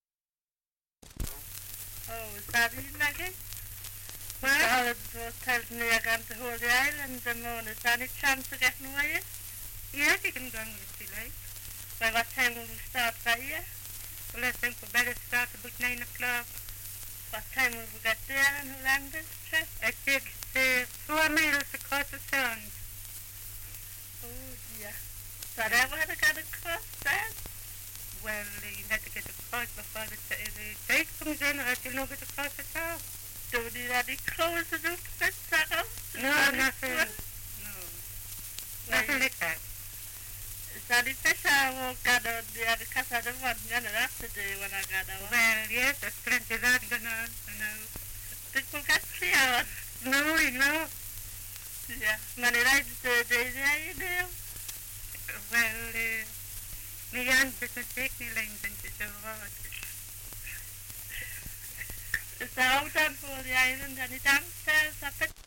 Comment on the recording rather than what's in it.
1 - Dialect recording in Newbiggin-by-the-Sea, Northumberland 78 r.p.m., cellulose nitrate on aluminium